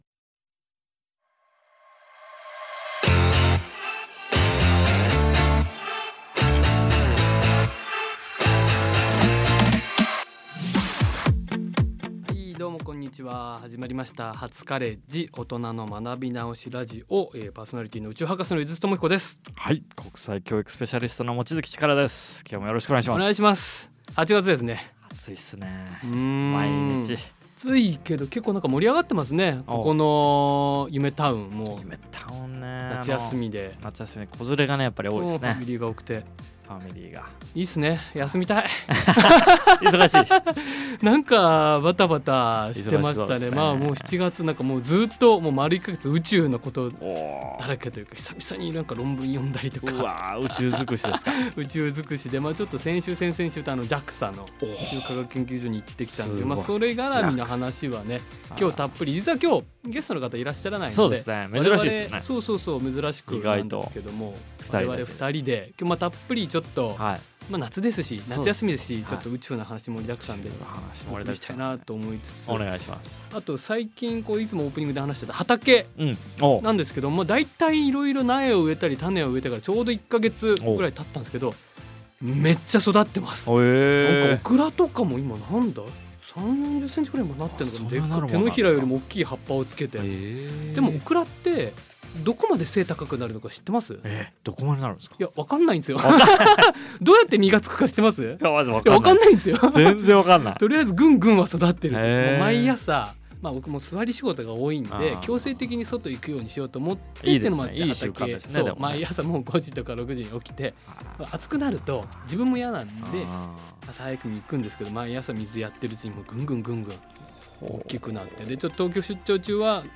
今回はゲストなしということで、宇宙トークスペシャル！ 夏に見ごろの星空の話から、流れ星のサイエンスについて。